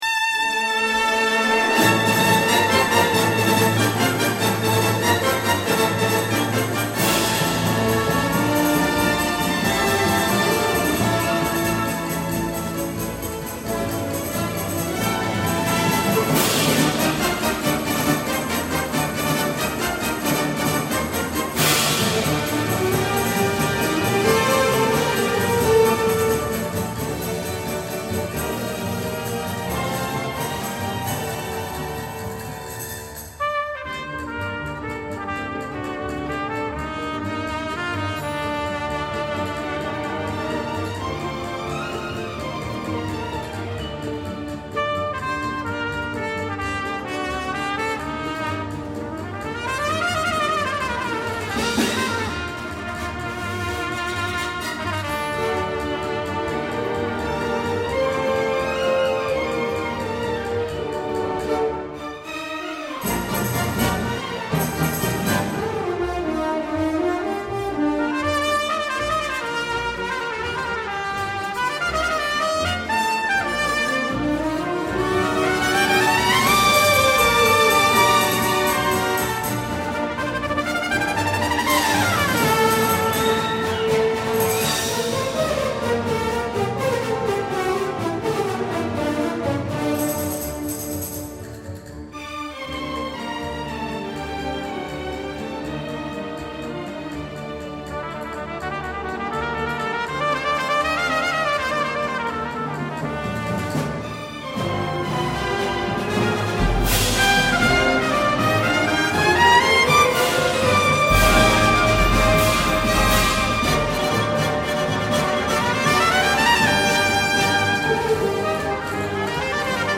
Please note: These samples are of varying quality.
Most were taken from live performances and are intended